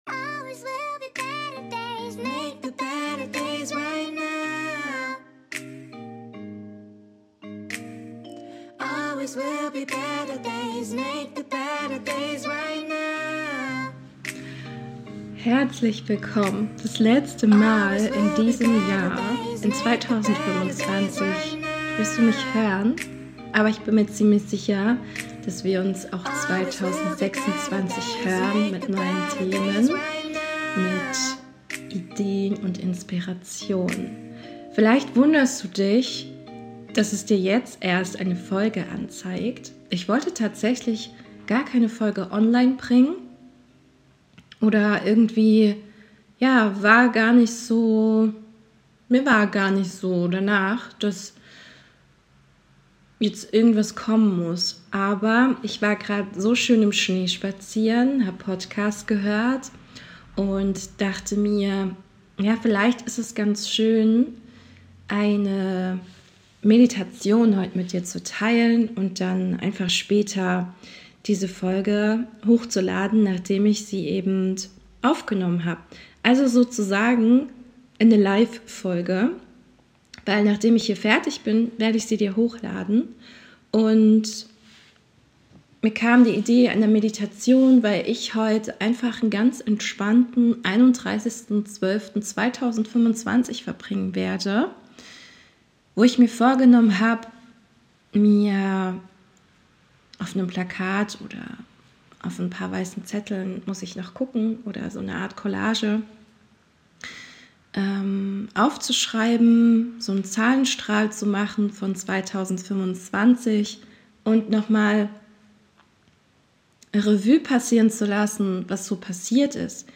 Beschreibung vor 3 Monaten Museumsmeditation - Deine Jahresabschlussmeditation 2025 Man kann das Leben nur vorwärts Leben und rückwärts verstehen Passend zu diesem Zitat entstand von Mir für Dich heute ganz spontan, diese Meditation. Ich lade dich ein, dein Jahr in Dankbarkeit und Fülle abzuschließen. Darauf zu schauen, was eigentlich schon alles da war und welche Geschenke auch in den schmerzvollen Momenten liegen.